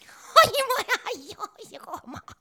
SOMETHING.wav